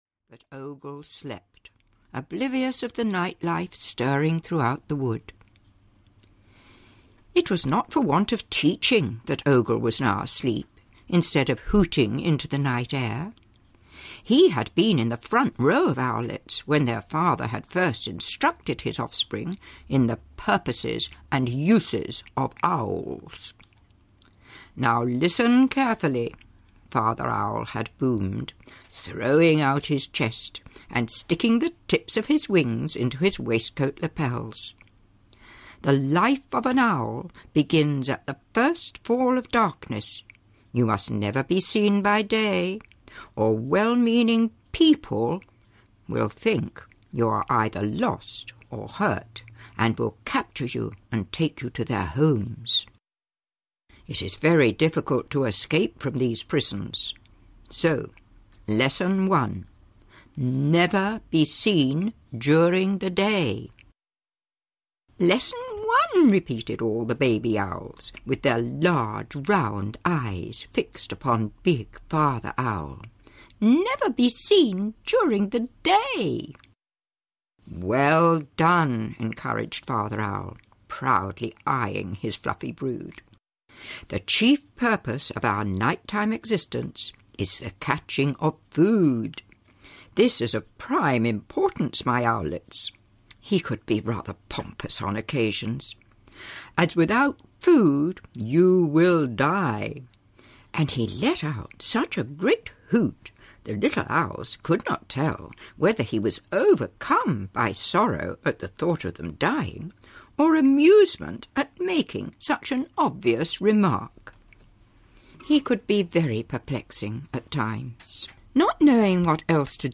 The Who's Afraid Stories (EN) audiokniha
Ukázka z knihy